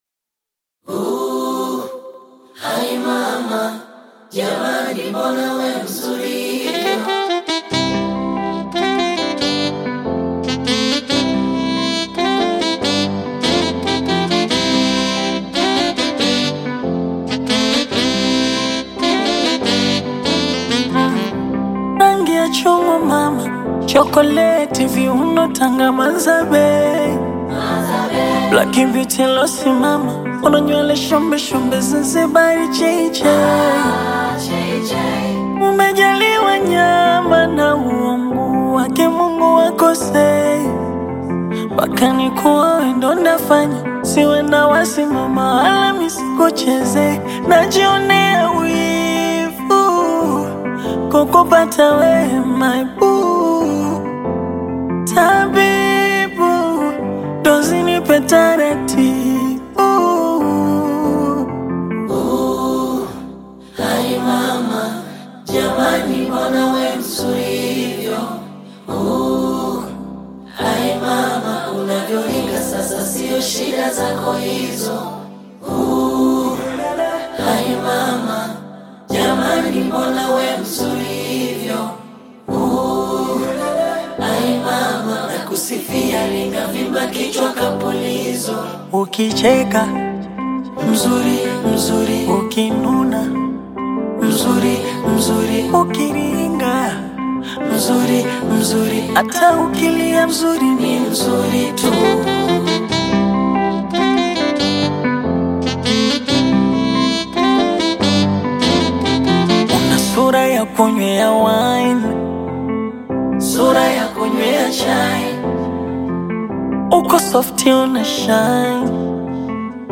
Tanzanian Bongo Fleva artist